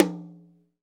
TOM TOM 92.wav